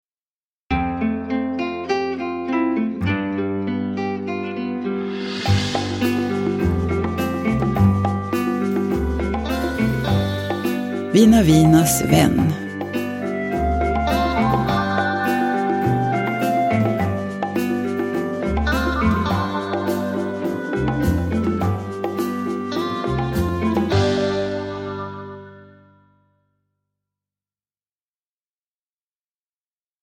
Vina Vinas vän – Ljudbok
Uppläsare: Jujja Wieslander